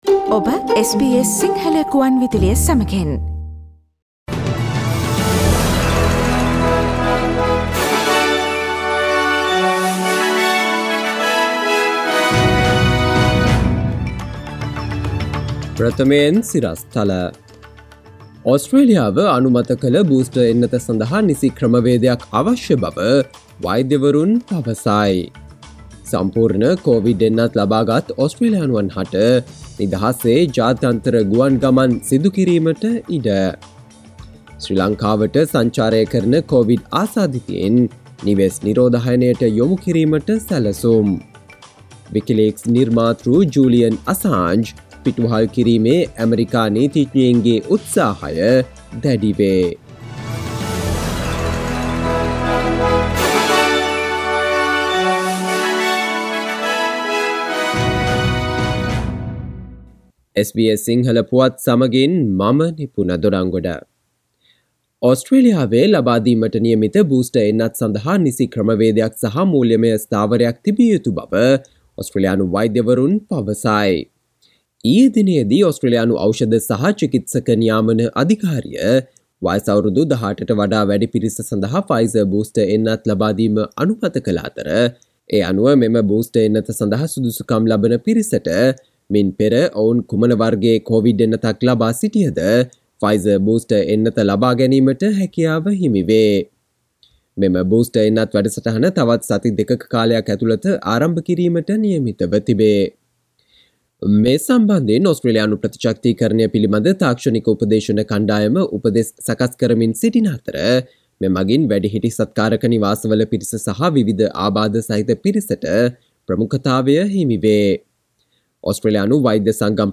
සවන්දෙන්න 2021 ඔක්තෝබර් 28 වන බ්‍රහස්පතින්දා SBS සිංහල ගුවන්විදුලියේ ප්‍රවෘත්ති ප්‍රකාශයට...